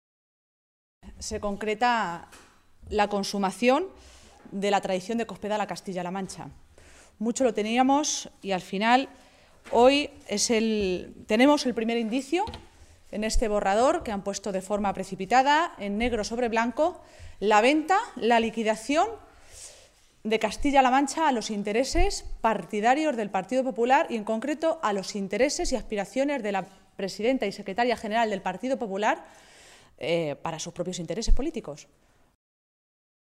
Maestre hacía estas declaraciones en una comparecencia ante los medios de comunicación, en Toledo, en la que valoraba la propuesta de Plan Hidrológico del Tajo que ha publicado hoy en el Boletín Oficial del Estado el Ministerio de Agricultura.
Cortes de audio de la rueda de prensa